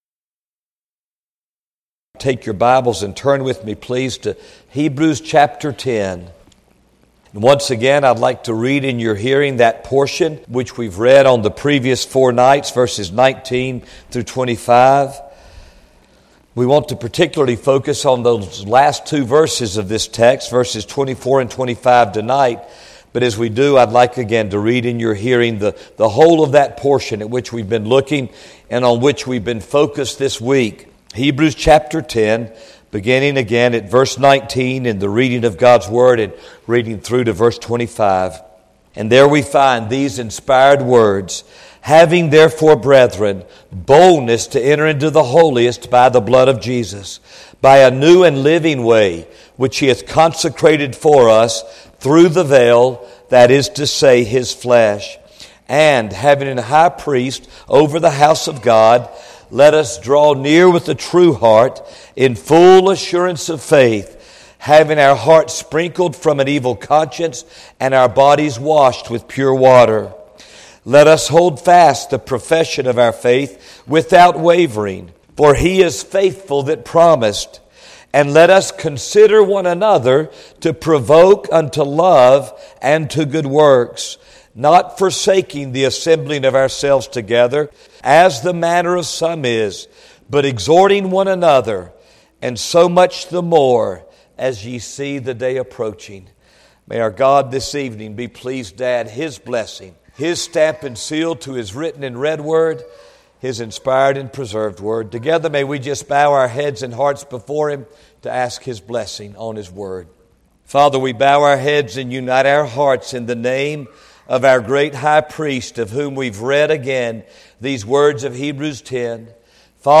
Session: Evening Session